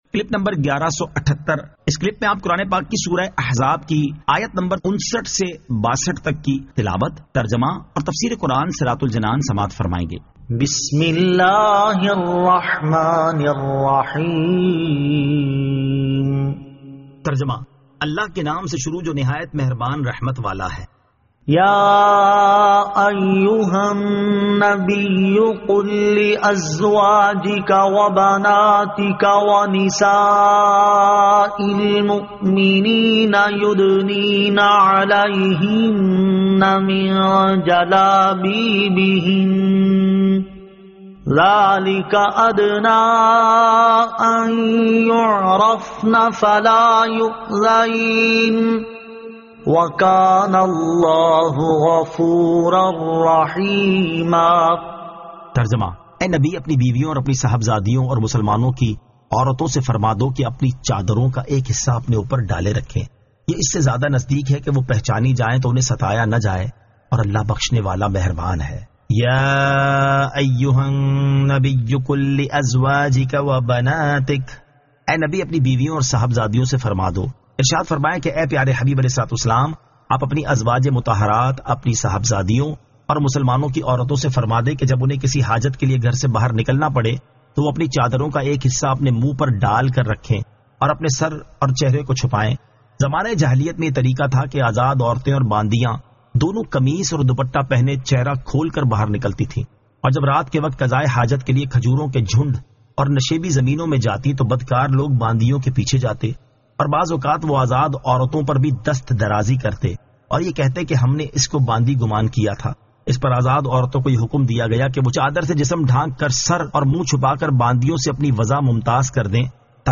Surah Al-Ahzab 59 To 62 Tilawat , Tarjama , Tafseer